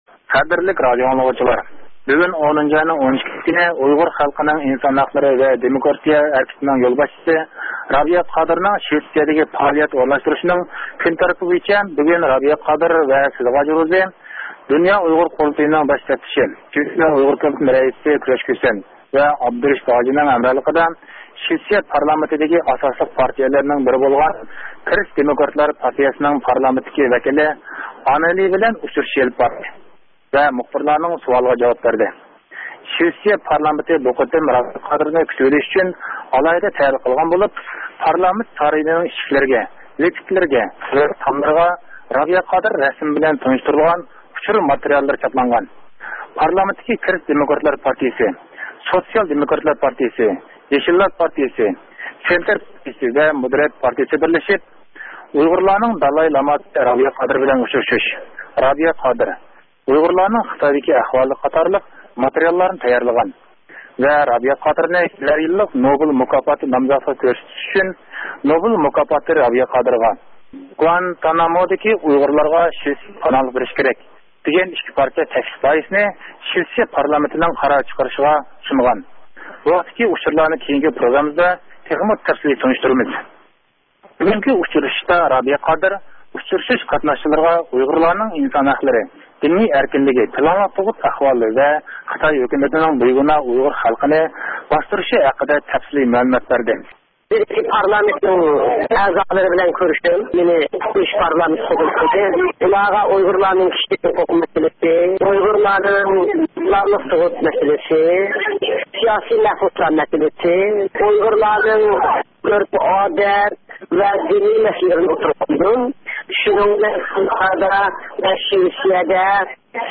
رابىيە قادىر خانىمنى زىيارەت قىلىش ئاساسىدا تەييارلىغان پروگراممىسىدىن ئاڭلاڭ.